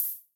IBI Open Hat 1.wav